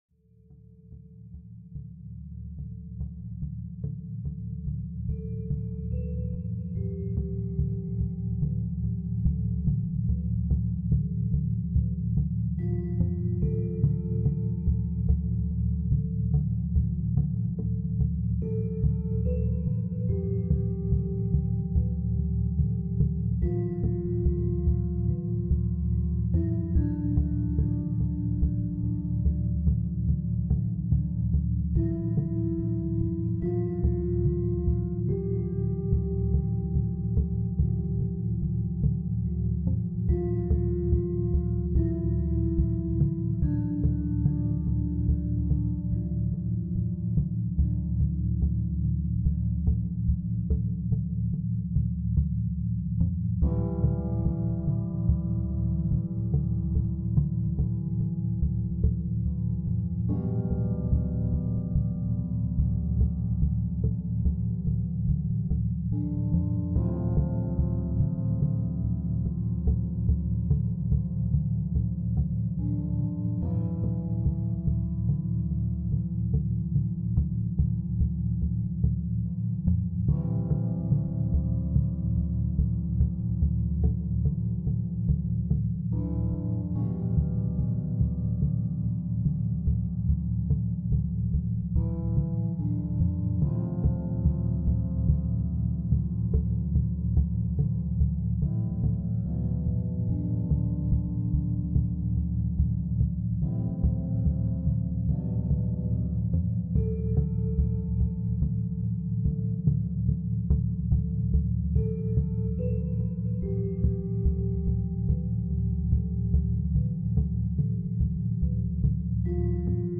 Zone d’étude essentielle · méthode scientifique nature focus pur